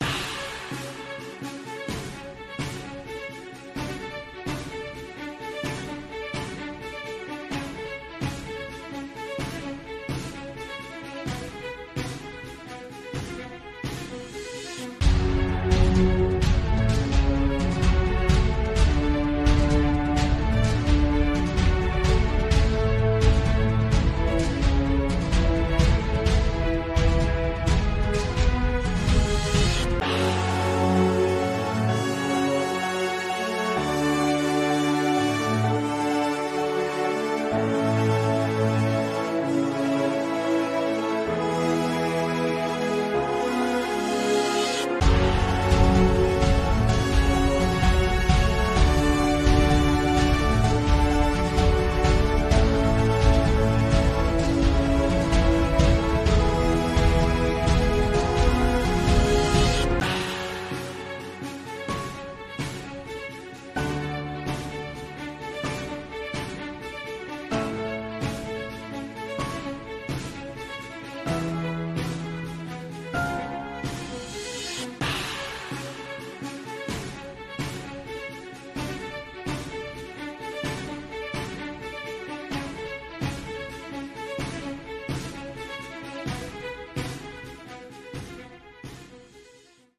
Orchestra_0329_9.mp3